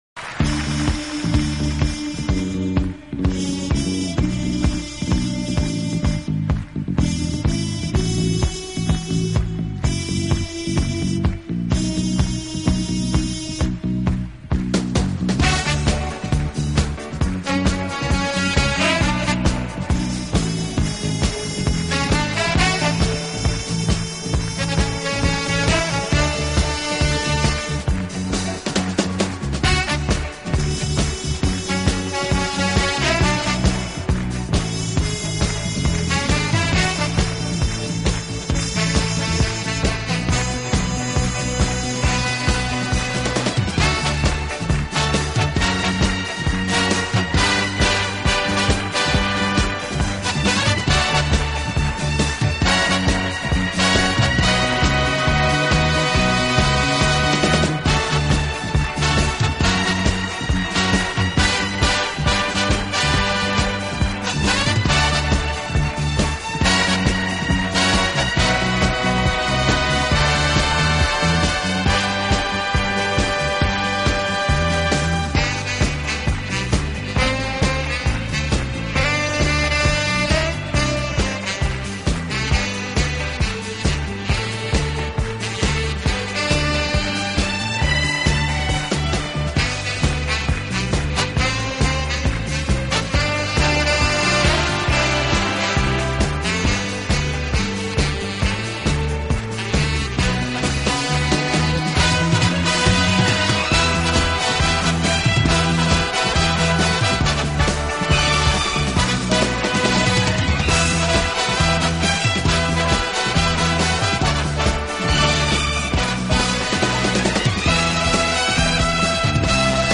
【轻音乐】
【顶级轻音乐】